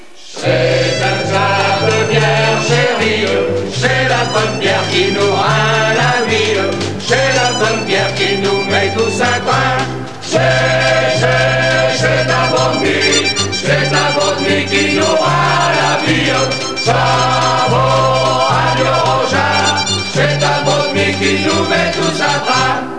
Ecoutez le refrain (496 koctets)